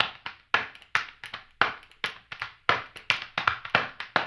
HAMBONE 09-L.wav